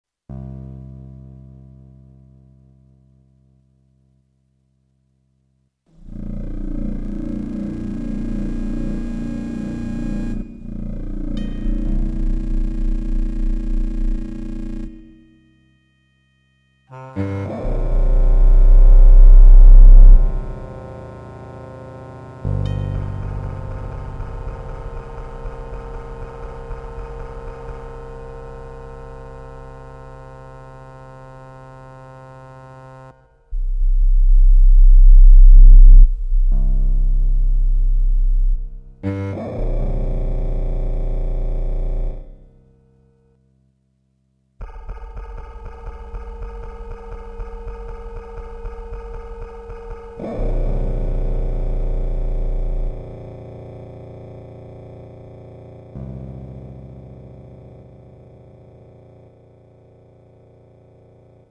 mGen is a music composition machine that reacts to listener preference in the generation of compositions.
Random notes, durations and sequences are used to generate an initial set of machines.